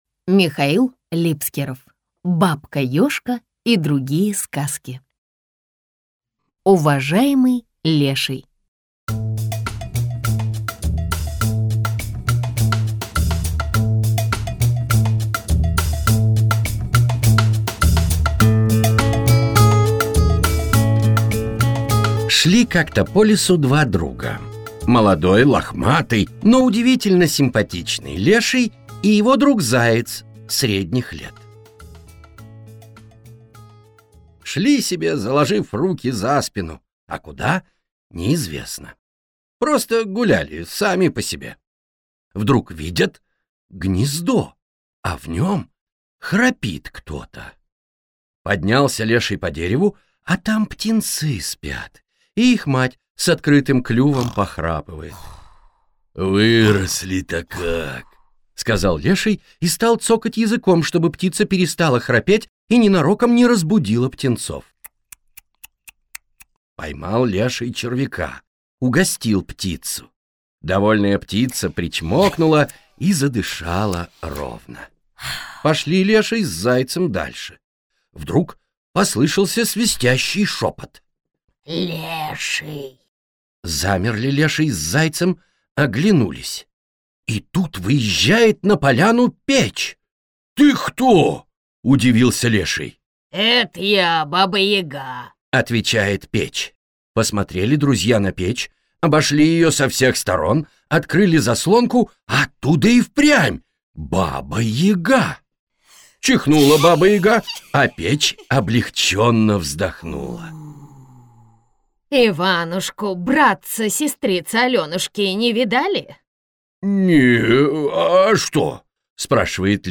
Аудиокнига Бабка Ёжка и другие сказки | Библиотека аудиокниг